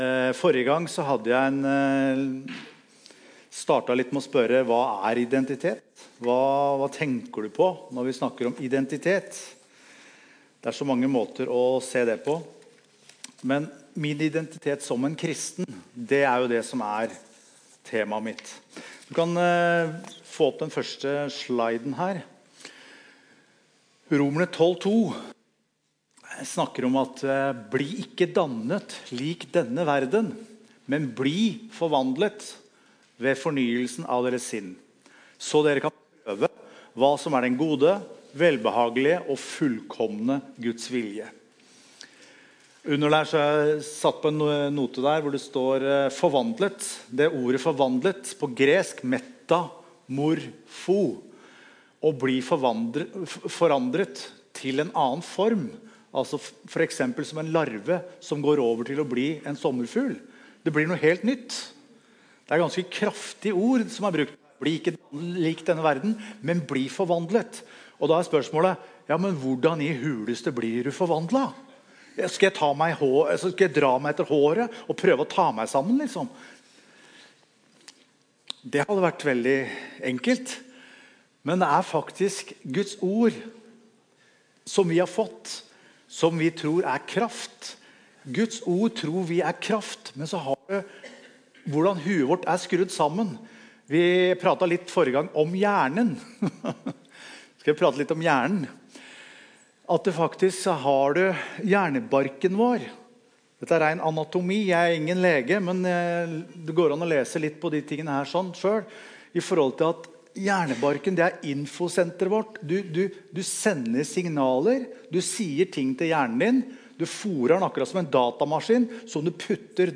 Evangeliehuset Romerike - Gudstjenester